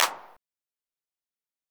nightcore-clap.wav